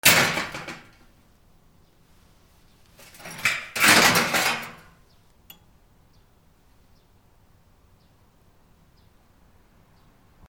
物音 金属
/ M｜他分類 / L01 ｜小道具 / 金属
『ガチャン』